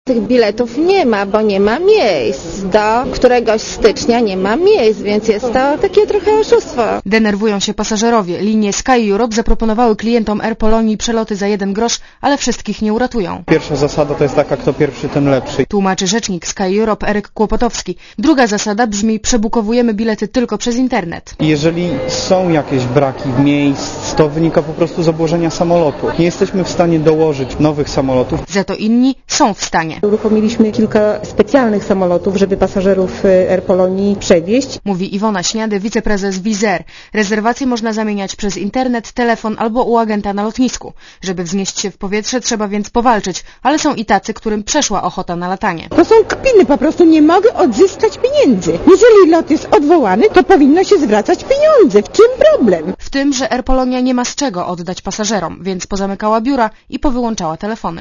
Źródło zdjęć: © PAP 06.12.2004 | aktual.: 06.12.2004 18:01 ZAPISZ UDOSTĘPNIJ SKOMENTUJ Relacja reportera Radia ZET